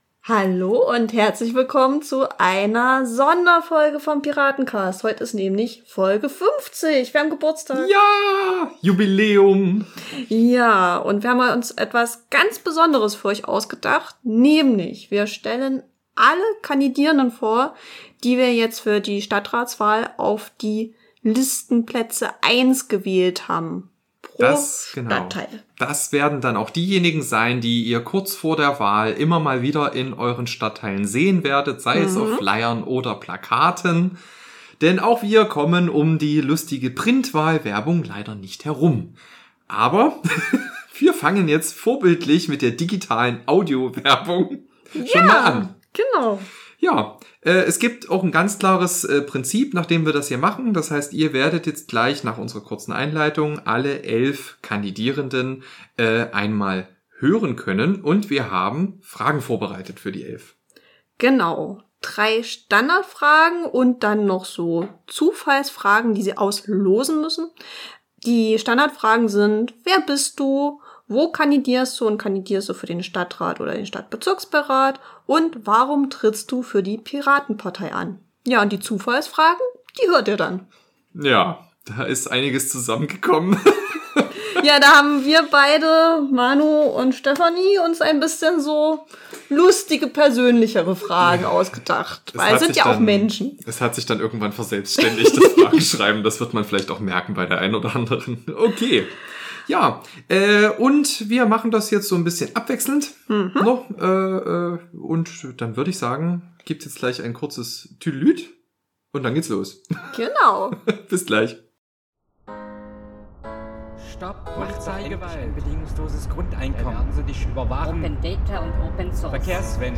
Juhu!Zur Feier des Tages haben wir besonders viele Pirat*innen zu Gast. In elf kleinen Interviews stellen wir euch unsere Spitzenkandidierenden für die elf Wahlkreise für die Stadtratswahlen 2024 vor.